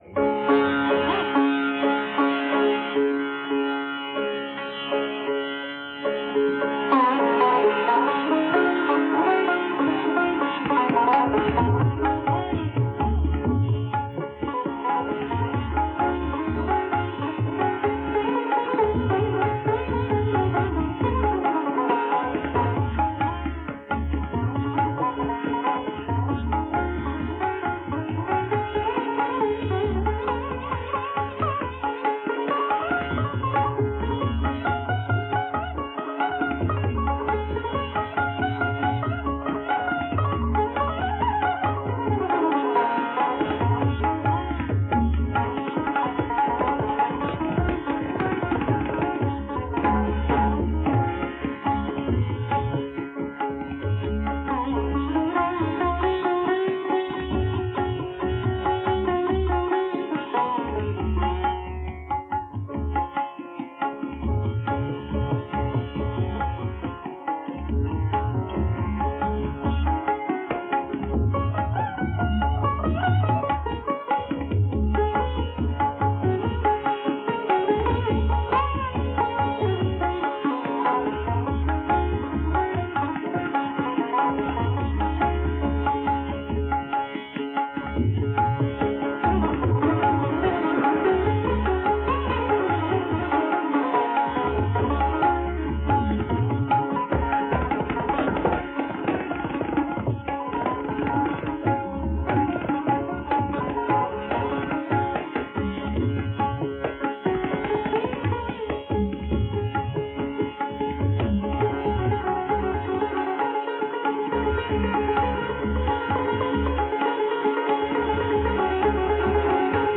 Sitar Gat
SITAR